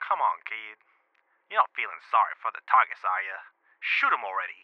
Voice Lines
Script Dialogue